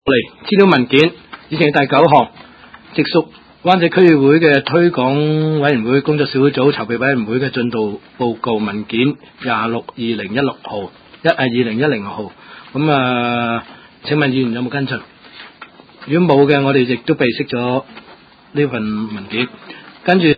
灣仔區議會第十五次會議
灣仔民政事務處區議會會議室